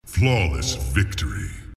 flawless victory mk x sound effects